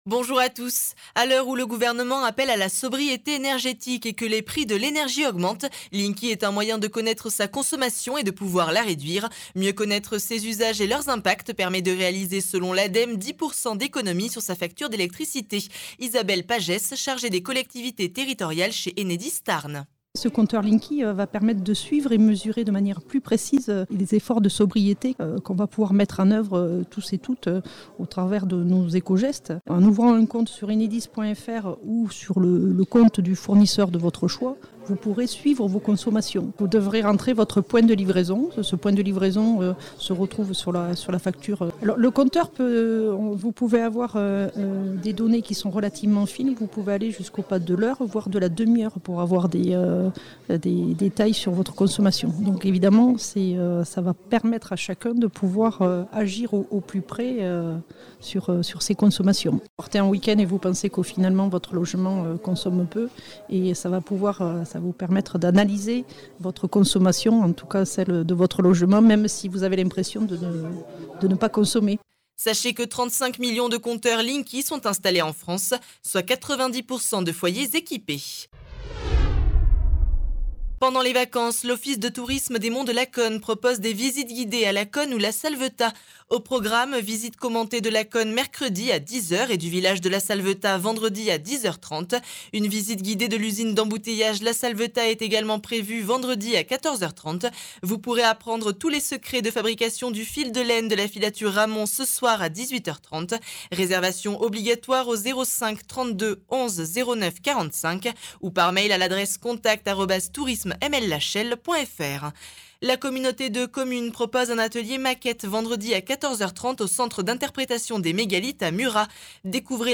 Actualités